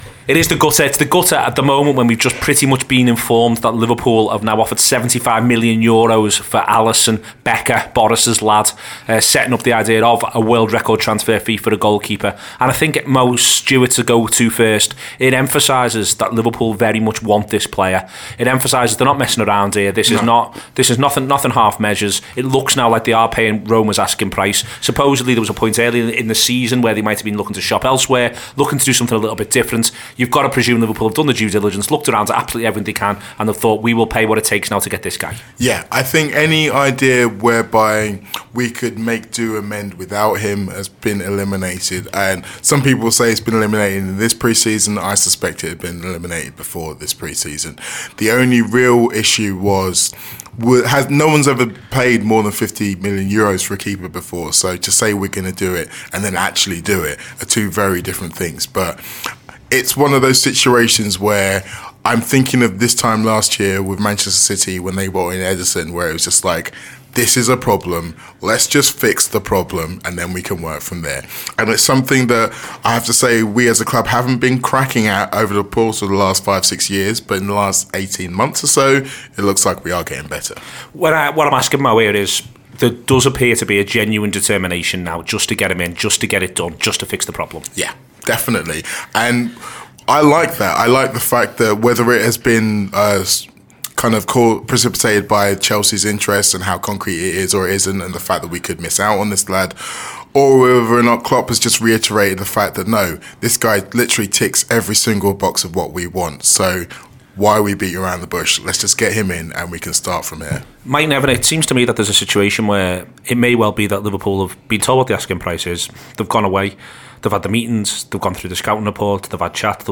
Part bulletin, part discussion, this is the Gutter at its best.